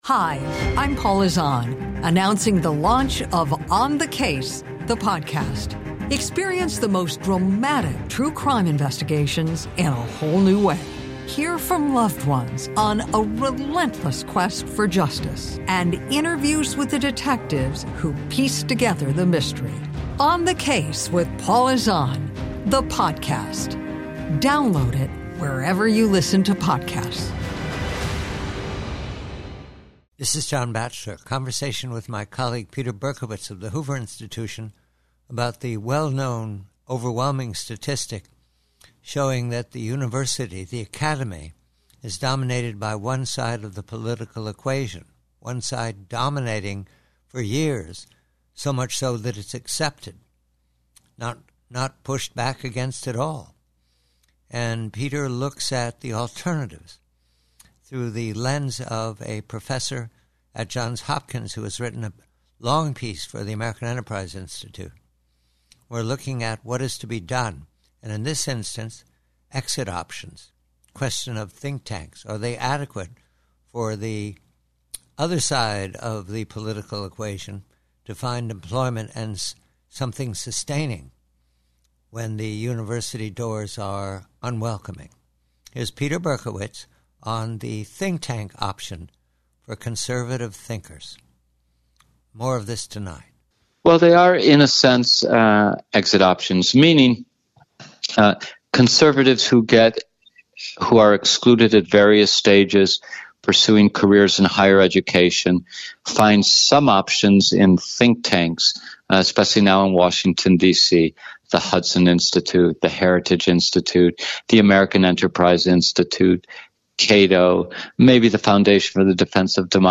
PREVIEW: ACADEMY: Conversation